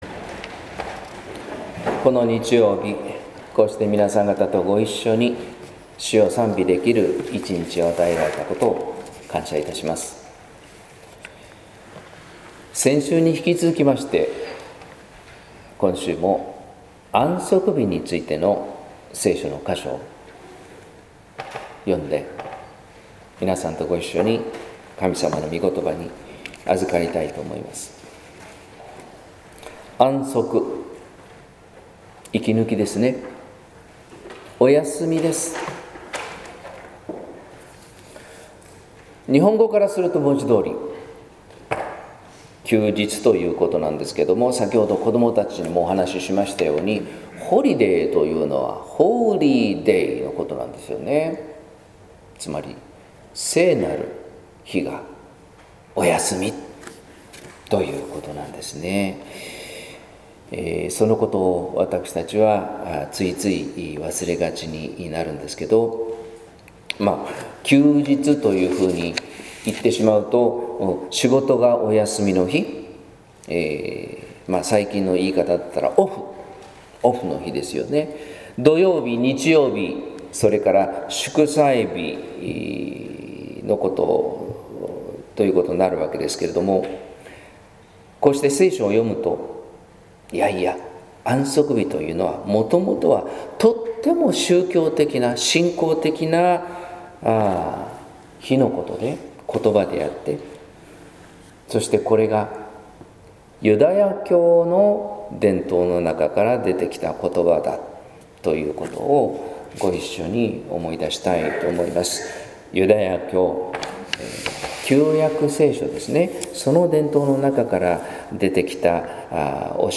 説教「かたくなな心をほぐしたい」（音声版） | 日本福音ルーテル市ヶ谷教会